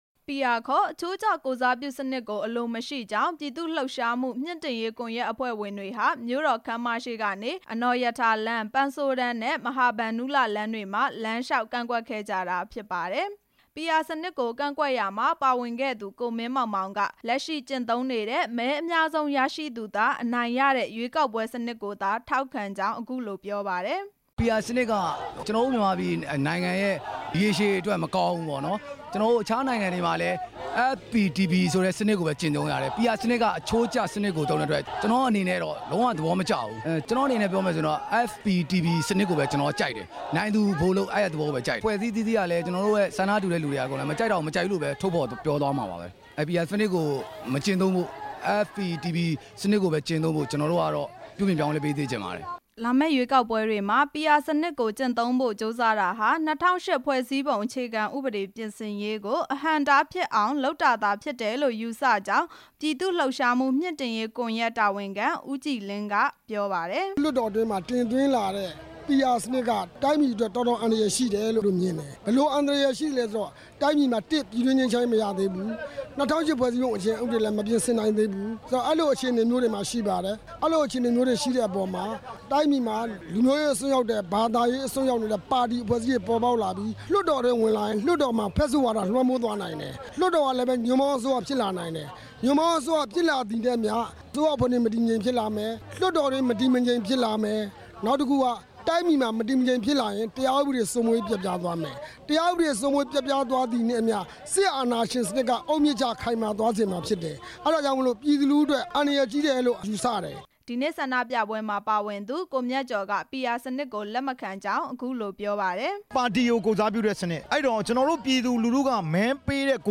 ဆန္ဒပြပွဲအကြောင်း တင်ပြချက်